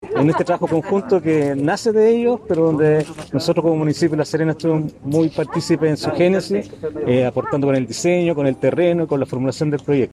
SERGIO-HERNRQUEZ-ALCALDE-SUBROGANTE-LA-SERENA.mp3